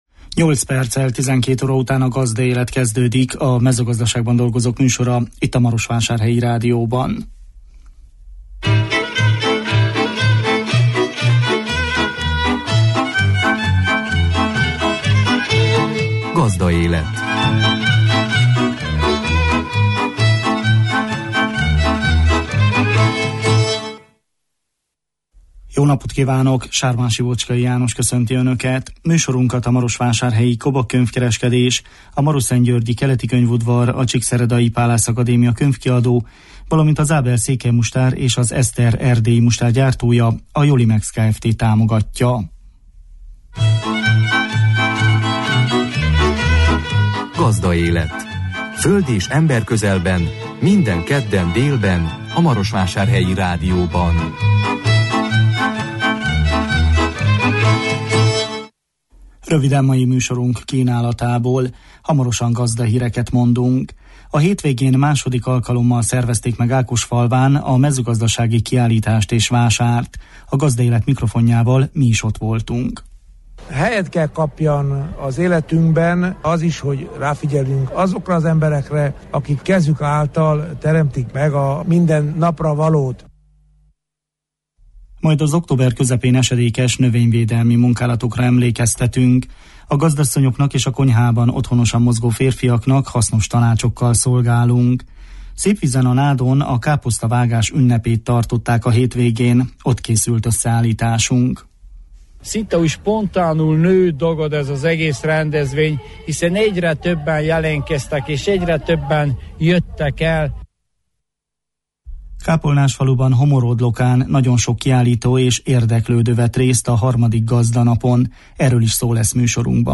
A hétvégén II. alkalommal szervezték meg Ákosfalván a mezőgazdasági kiállítást és vásárt. A Gazdaélet mikrofonjával mi is ott jártunk. Majd az október közepén esedékes növényvédelmi munkálatokra emlékeztetünk.
Szépvízen a Nádon a káposztavágás ünnepét tartották a hétvégén. Ott készült összeállításunk.